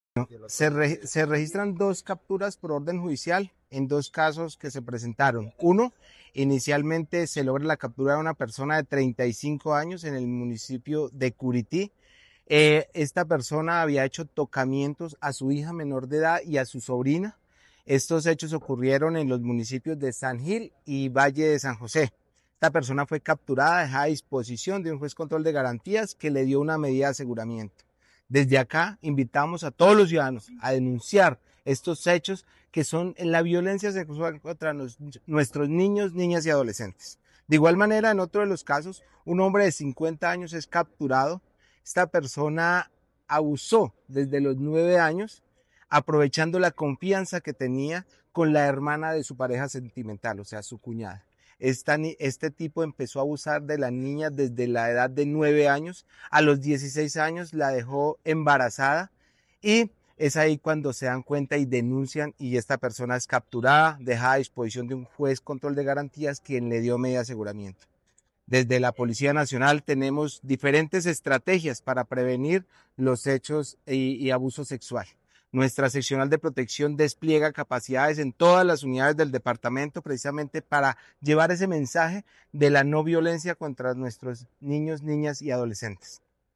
Coronel Néstor Rodrigo Arévalo Montenegro Comandante Departamento de Policía Santander